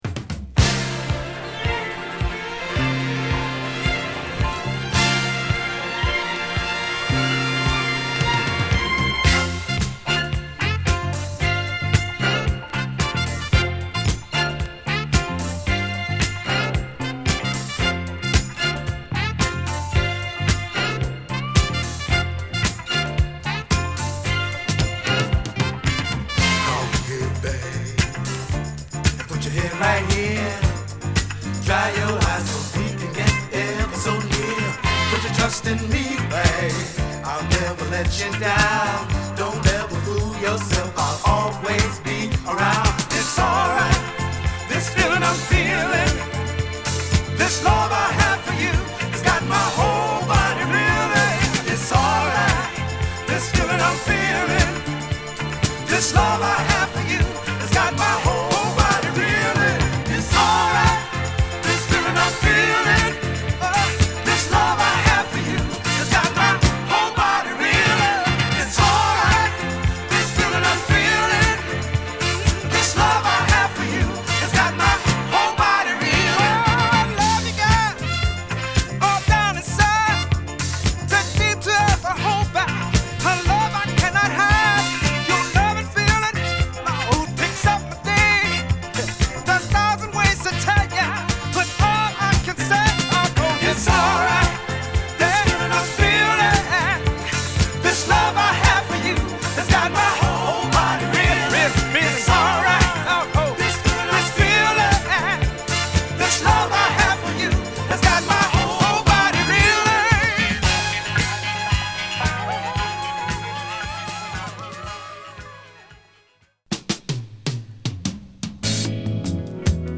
＊A3チリパチ有り